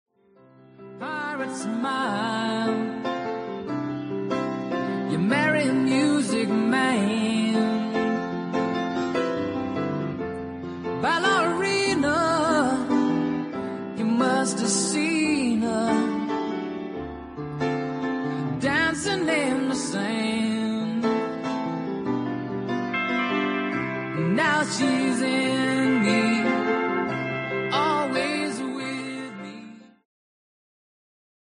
1. 70'S ROCK >